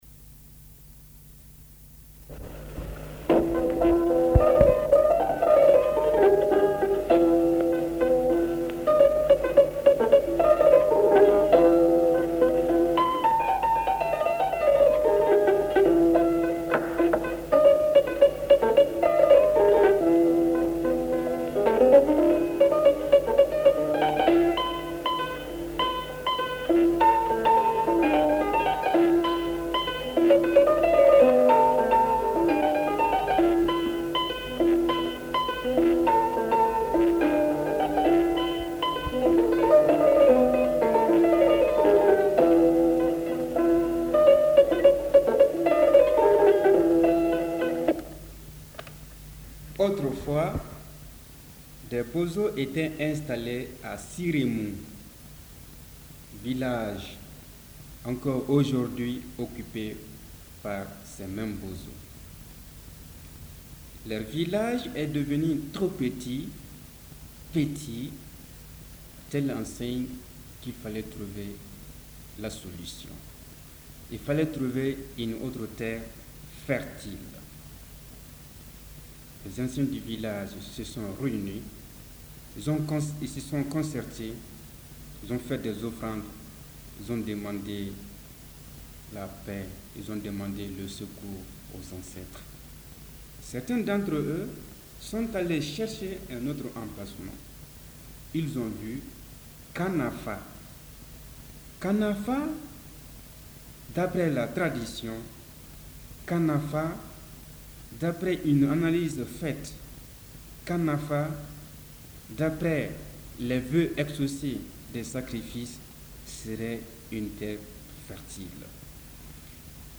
Fondation du village de djenné : Conte malien · OmekaS By DataCup · Omekas - Mali